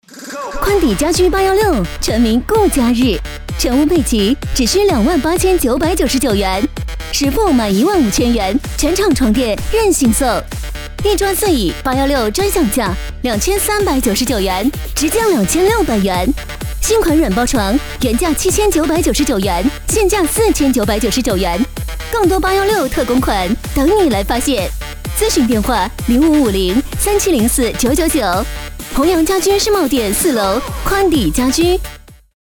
女107-【促销】（家居）
女107--促销--家居-.mp3